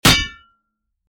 Download Metal sound effect for free.
Metal